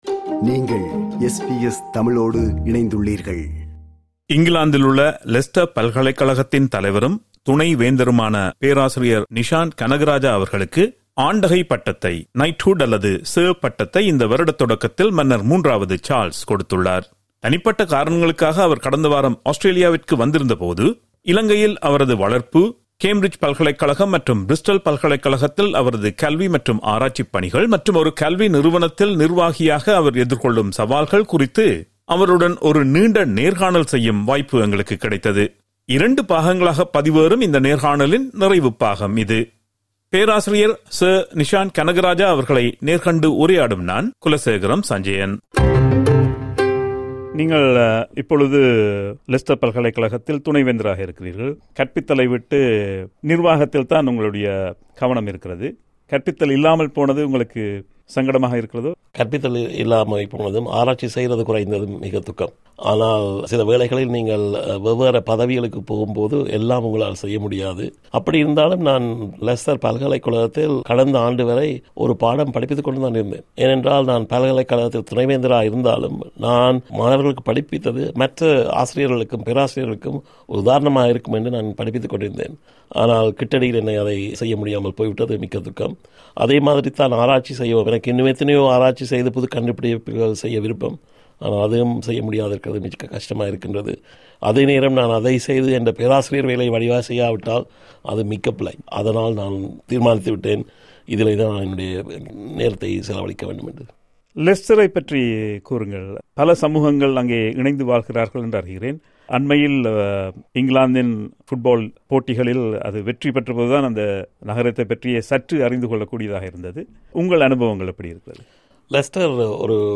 இலங்கையில் அவரது வளர்ப்பு, கேம்பிரிட்ஜ் பல்கலைக்கழகம், மற்றும் பிரிஸ்டல் பல்கலைக்கழகத்தில் அவரது கல்வி மற்றும் ஆராய்ச்சி பணிகள் மற்றும் ஒரு கல்வி நிறுவனத்தில் நிர்வாகியாக அவர் எதிர்கொள்ளும் சவால்கள் குறித்து அவருடன் ஒரு நீண்ட நேர்காணல் செய்யும் வாய்ப்பு எங்களுக்குக் கிடைத்தது. இரண்டு பாகங்களாகப் பதிவேறும் இந்த நேர்காணலின் நிறைவுப் பாகம் இது.